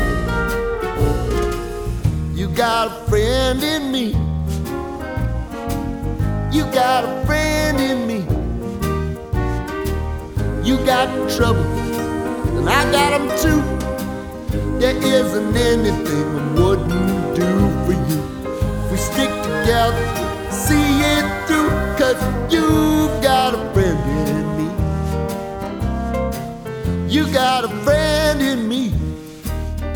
Жанр: Соундтрэки